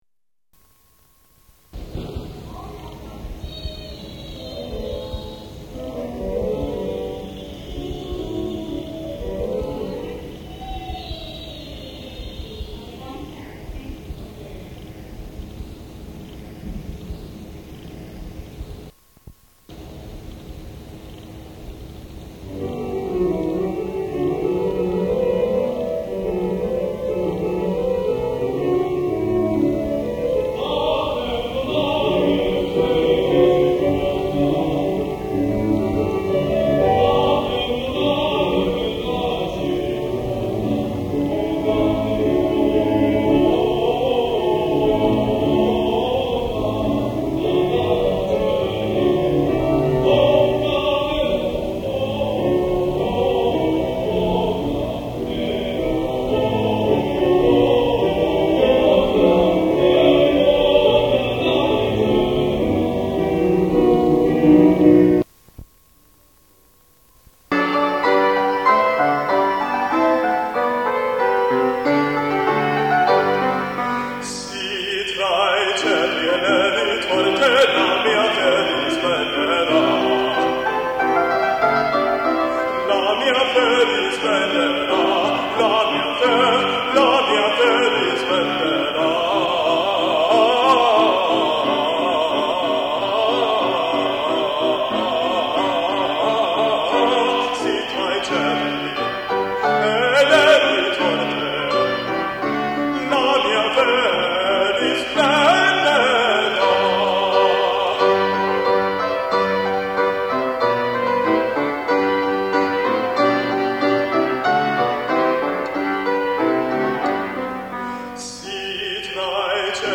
The following recording is a compilation of performance excerpts from 1984 to 1997 that demonstrate various approaches to singing using the same voice, yet with vastly different vocal techniques.